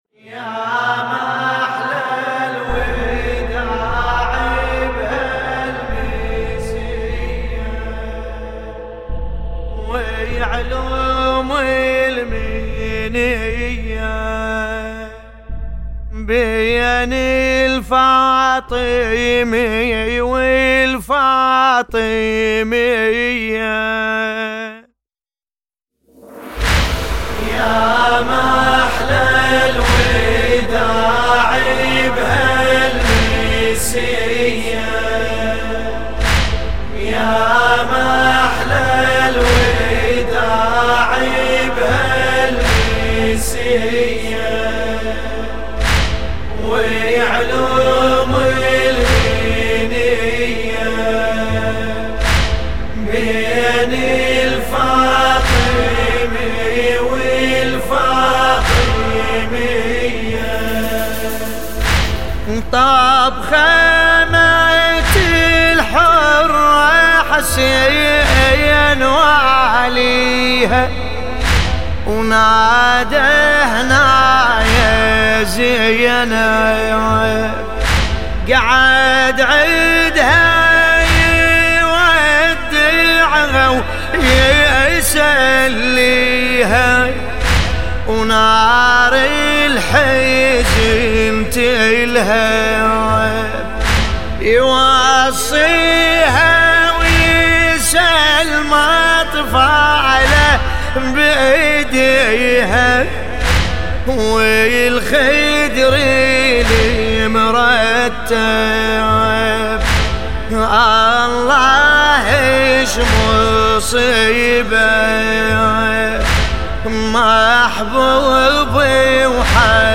سینه زنی